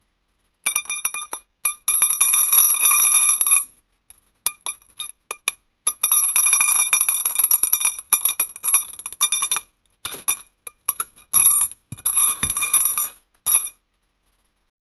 The sounds of young people at a giant outdoor concert. No music is playing yet. Everyone is laughing and cheering, and they start clapping in sync at 112 bpm. Some people are close and others are far away. There is excitement in the air. 0:15